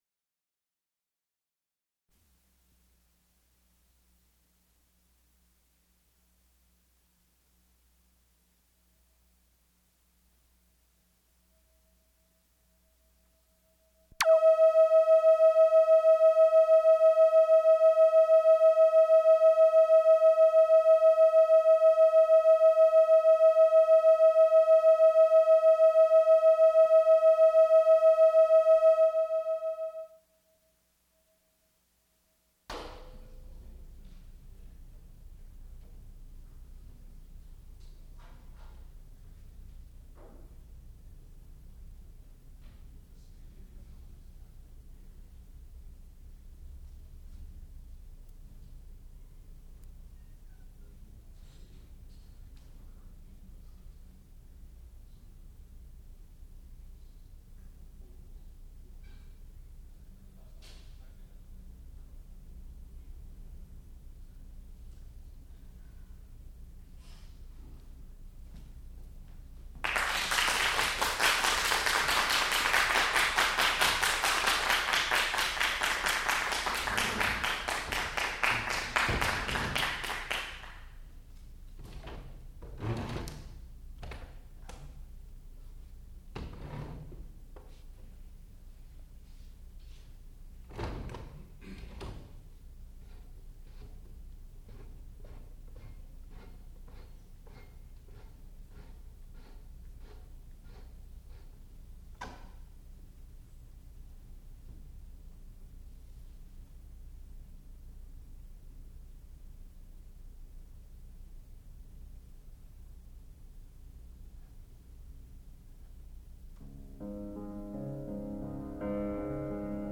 sound recording-musical
classical music
piano